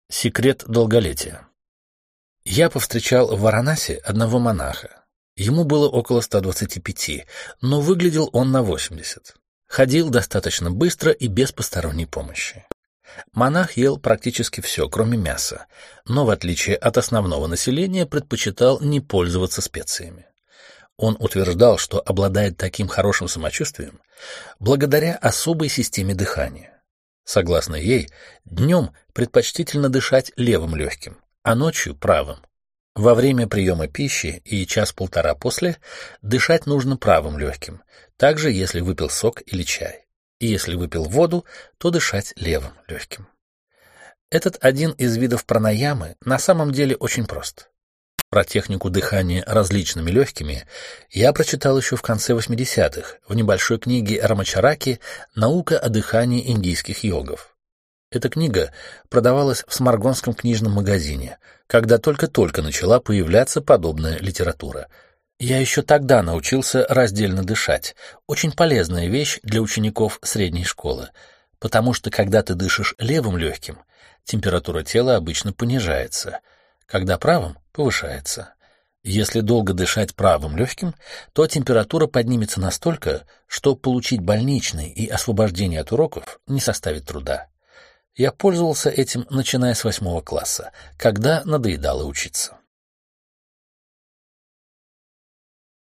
Аудиокнига 21 стакан. Истории Йогеша, записанные Анро | Библиотека аудиокниг